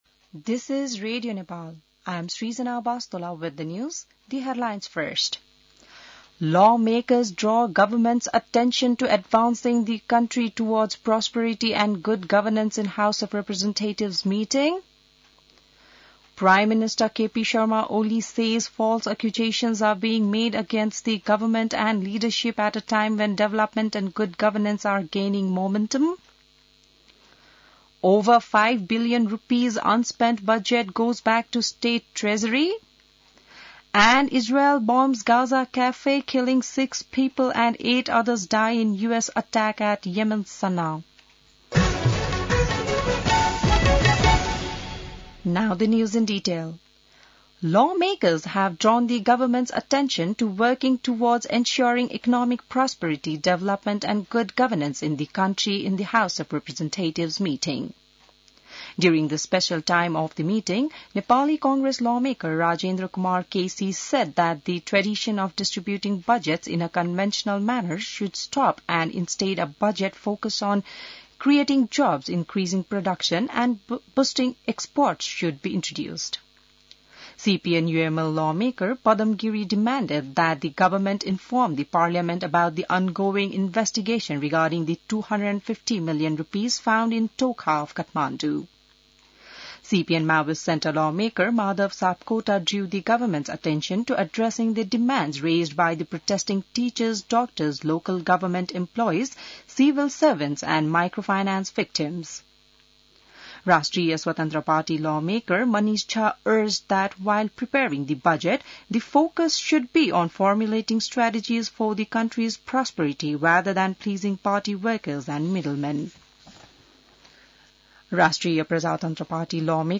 बिहान ८ बजेको अङ्ग्रेजी समाचार : १५ वैशाख , २०८२